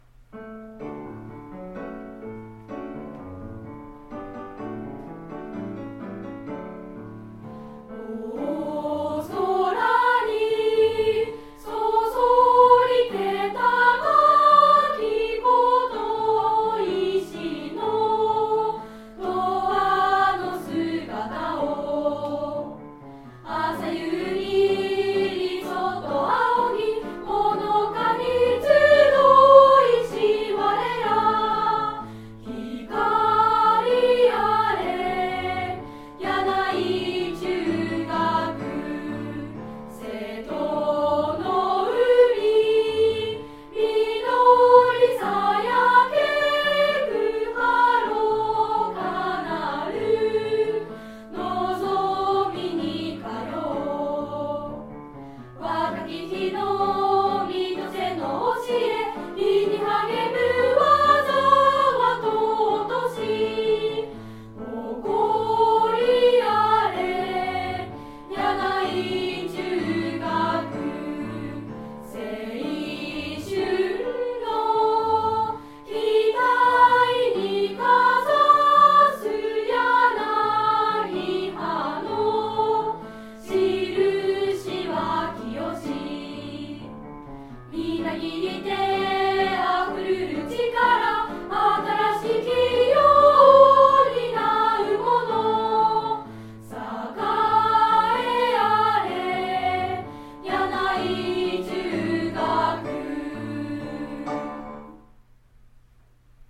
バイオリン
ピアノ伴奏
校歌演奏 [その他のファイル／2.08MB]